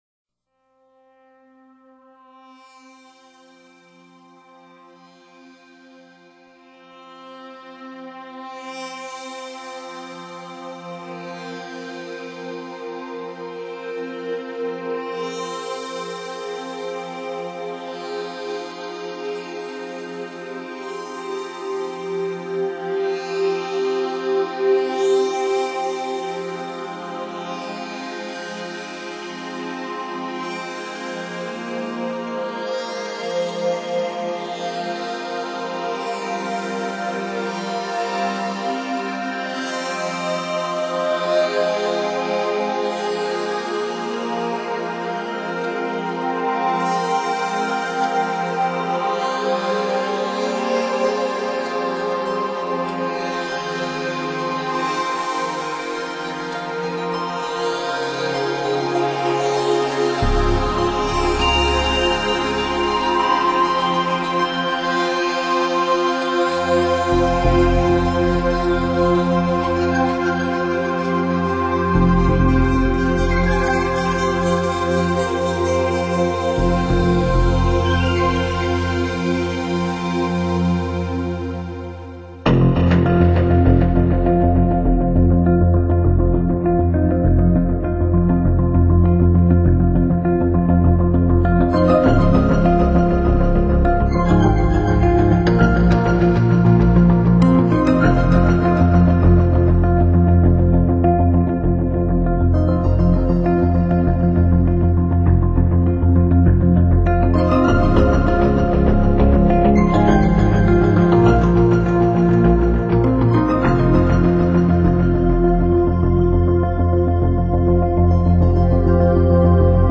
主题鲜明的钢琴演奏专辑，琢磨出钢琴静谧、激情的双面美感
最后曲式末了，场景进入月宫，空间弥漫著充满未来感和迷离感的飘渺音色。